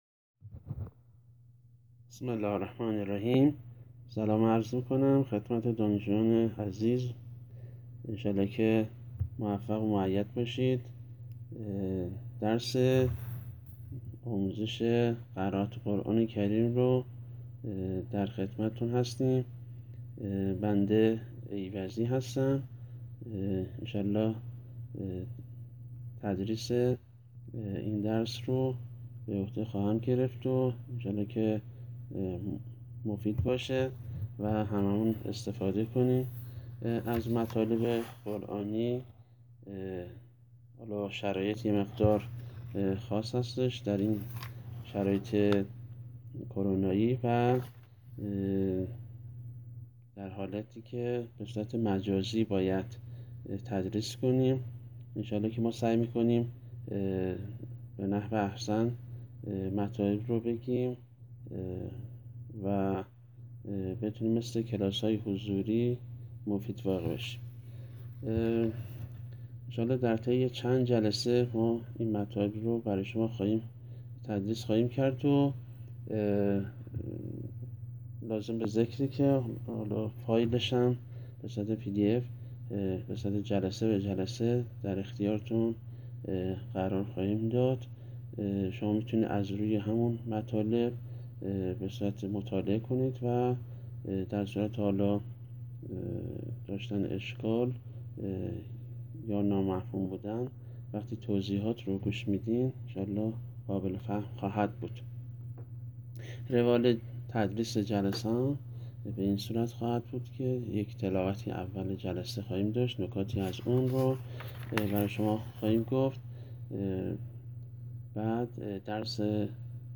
جلسه اول تدریس قرآن